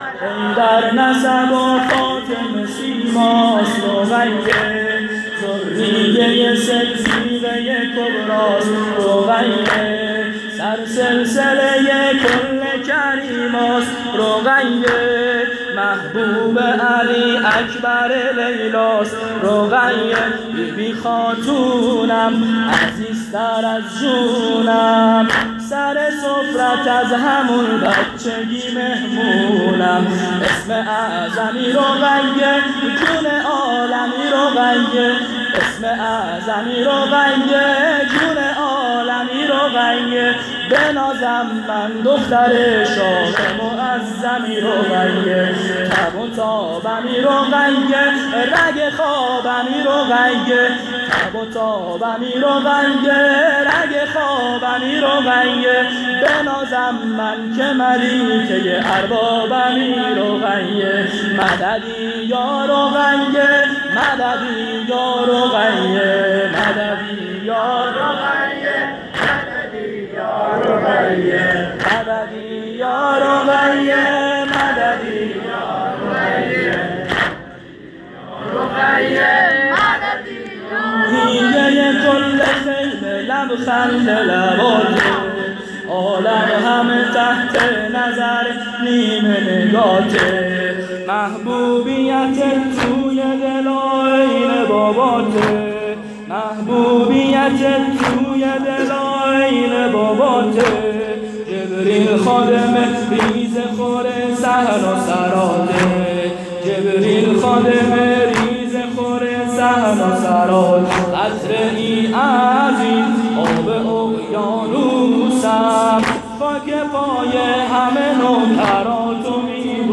شب سوم محرم98 هیئت میثاق الحسین (ع) سیستان